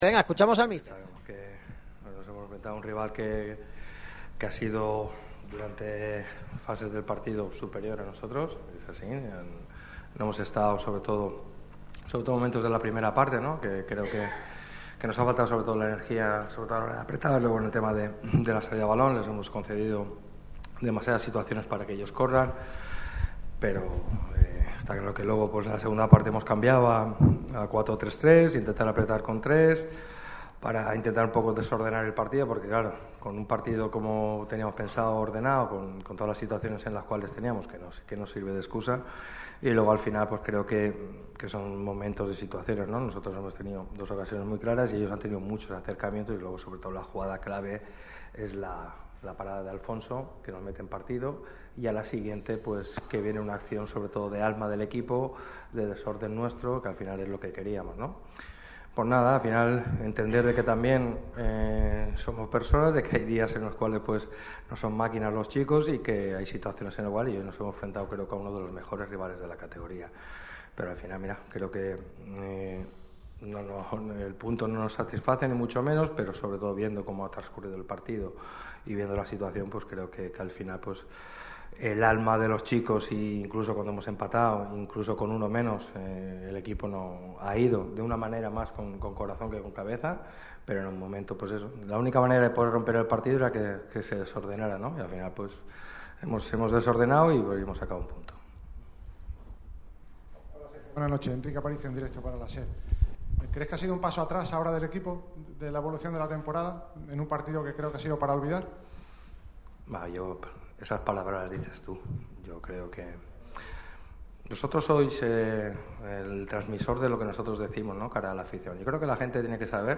La rueda de prensa más tensa de Pellicer... - Radio Marca Málaga
El de Nules se mostró muy protector con sus jugadores en la rueda de prensa después del empate del equipo ante el Córdoba CF.